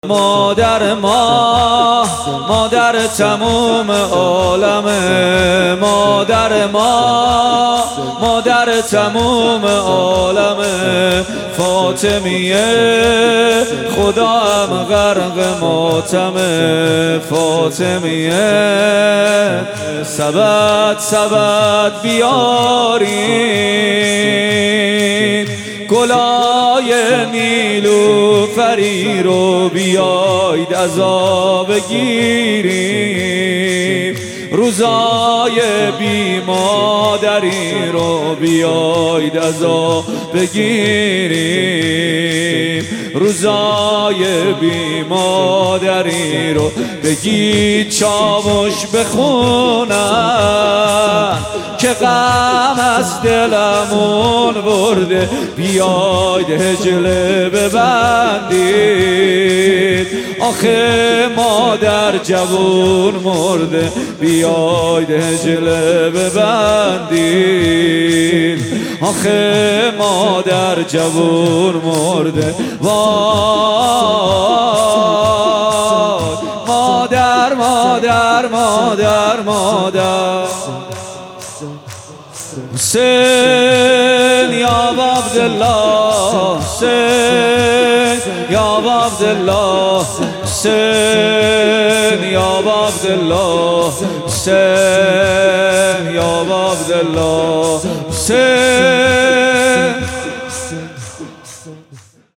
مادر تموم عالم محمدحسین پویانفر | ایام فاطمیه 1399 | پلان 3